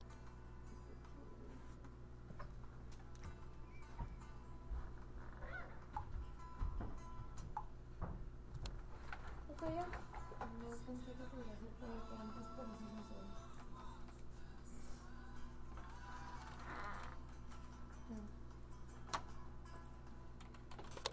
描述：午休时在办公室里的餐厅录音。使用奥林巴斯VN480录音机录制。
Tag: 办公室 餐厅